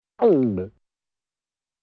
Nervous Gulp